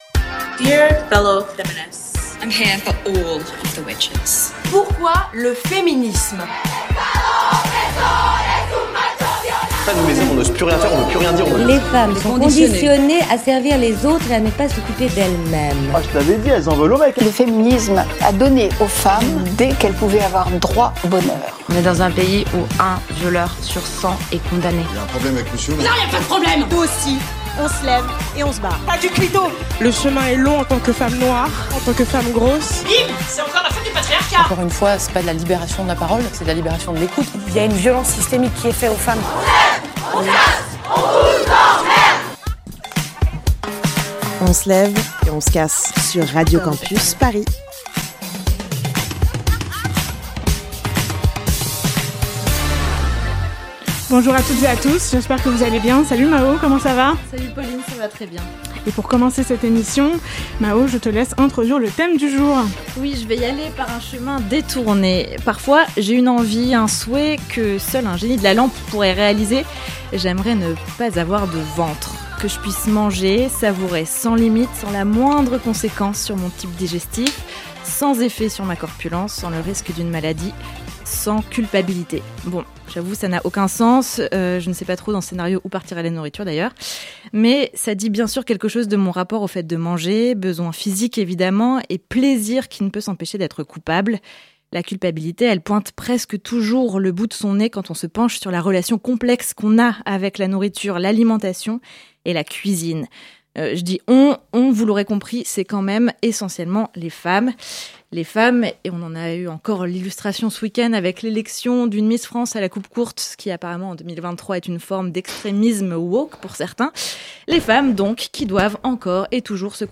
Partager Type Magazine Société samedi 30 décembre 2023 Lire Pause Télécharger Mais où sont les femmes dans l'histoire de la gastronomie ?